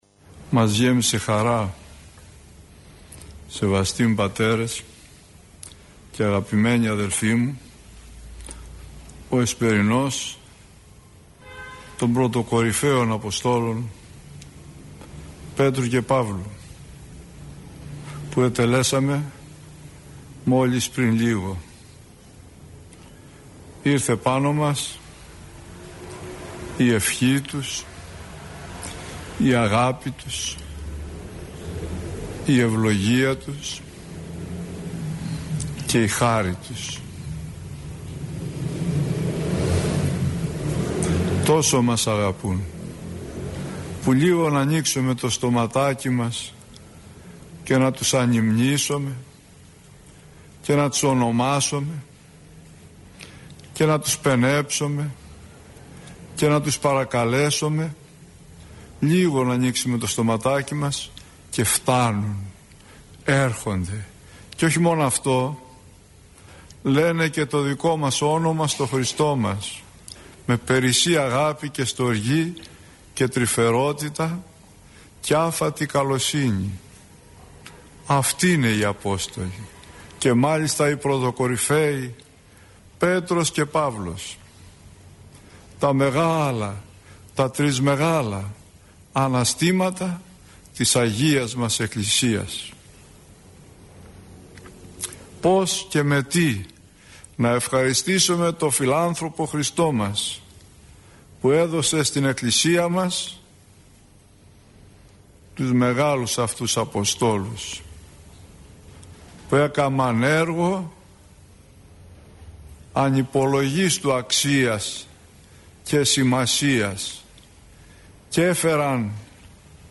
Οι Απόστολοι Πέτρος και Παύλος – ηχογραφημένη ομιλία
Η εν λόγω ομιλία αναμεταδόθηκε από τον ραδιοσταθμό της Πειραϊκής Εκκλησίας.